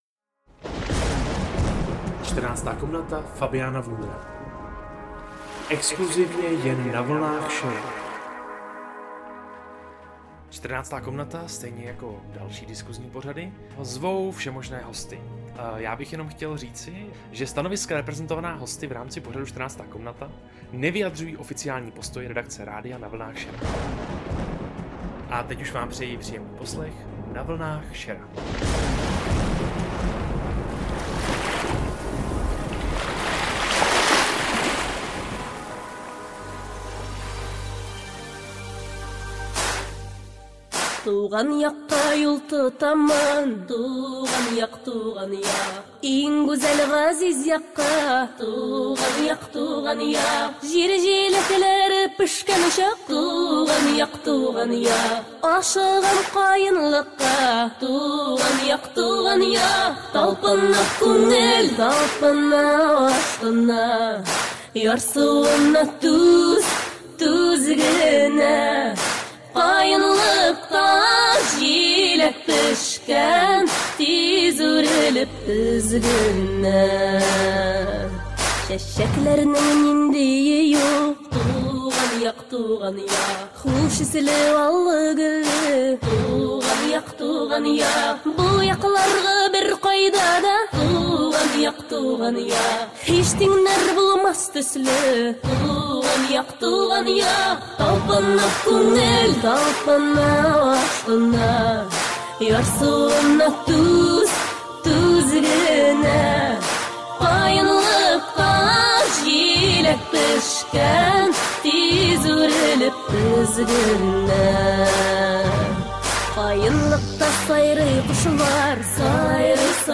To se dozvíte v exkluzivních rozhovoru